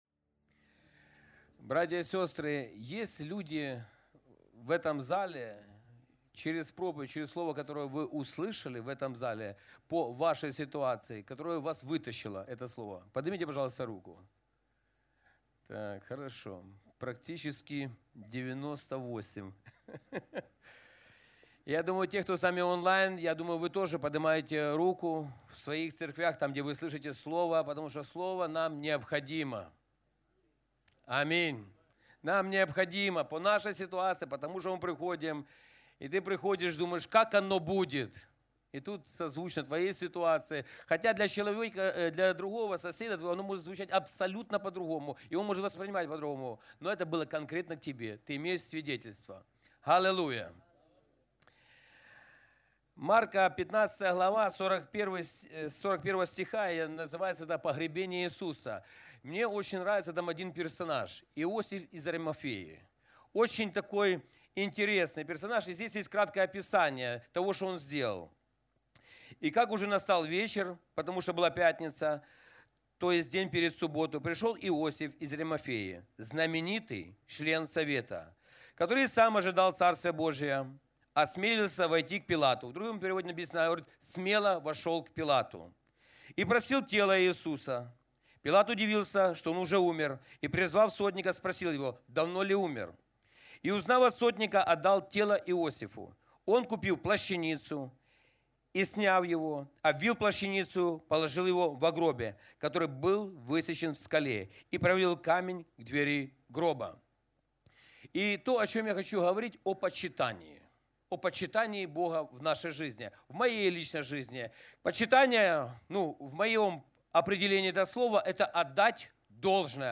Общецерковное служение
Почитание Бога Аудио проповедь